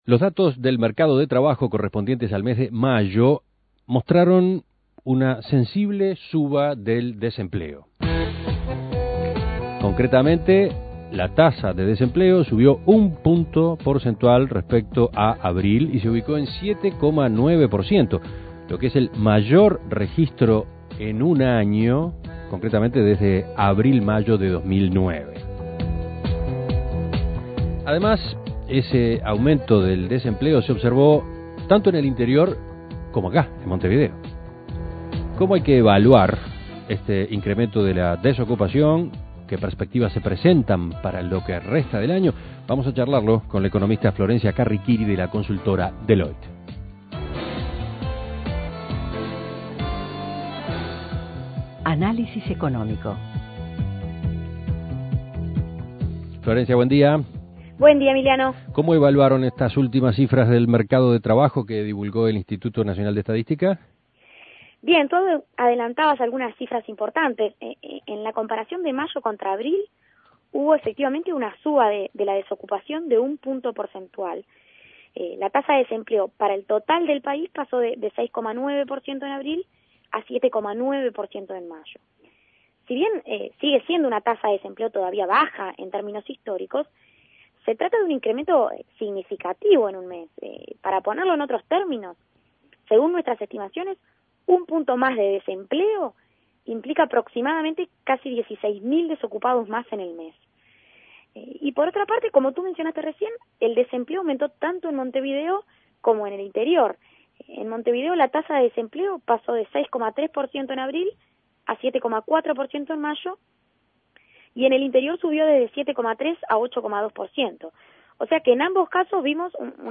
Análisis Económico El desempleo mostró un alza significativa en mayo.